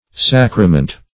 Sacrament \Sac"ra*ment\, v. t.